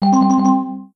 nudge.wav